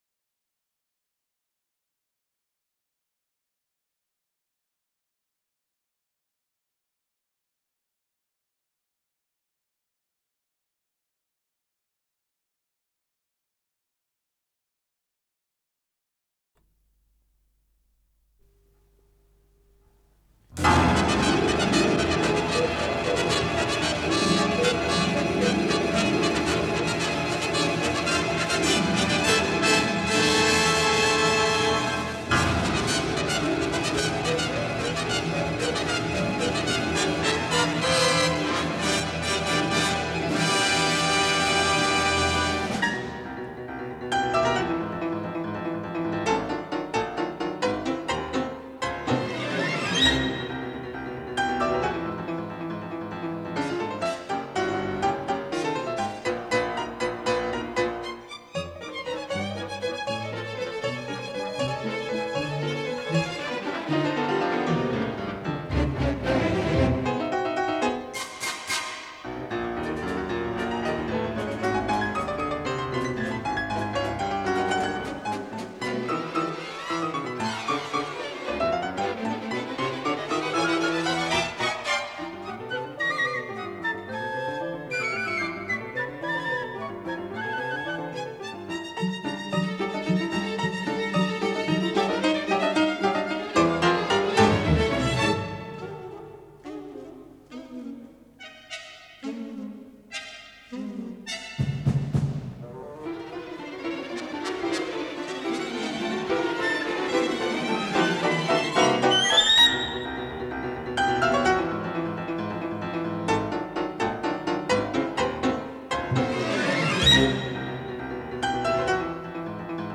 фортепиано
Концерт-сюита для фортепиано и оркестра